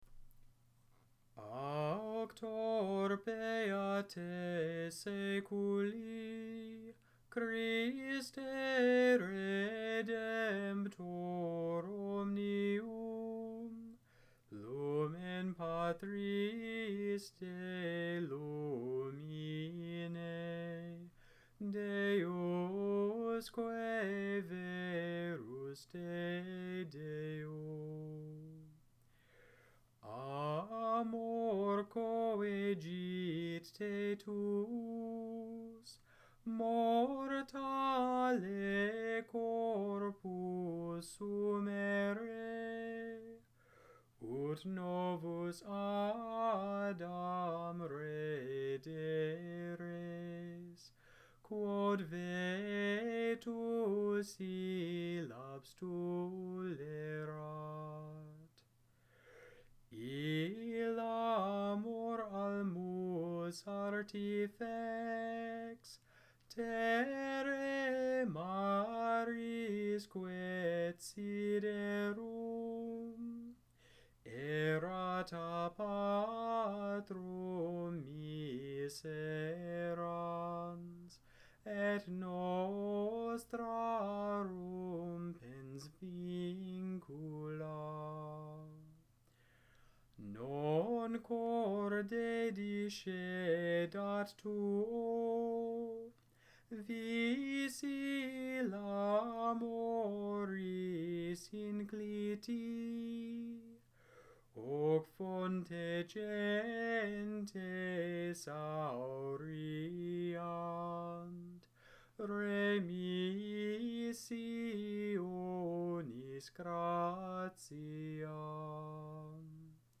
Gregorian chant audios